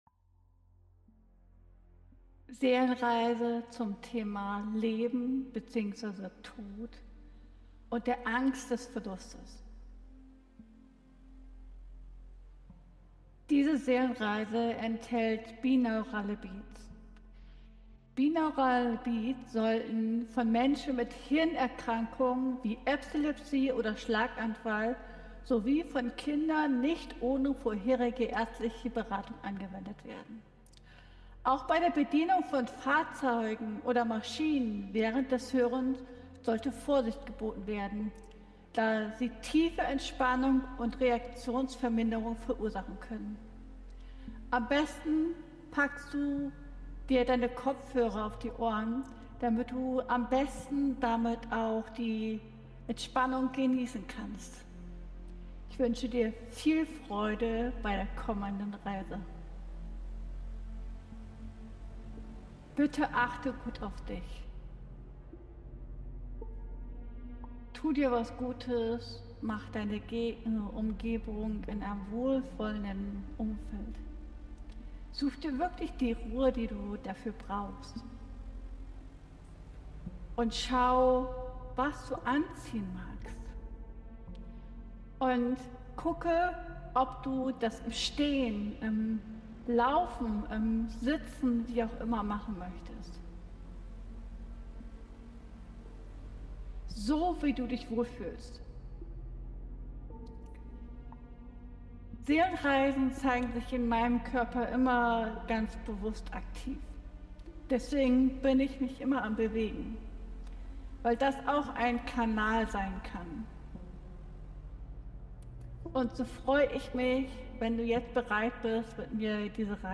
Seelenreise: Das tiefe Thema Leben und Tod mit Binauralen Beats ~ Ankommen lassen Podcast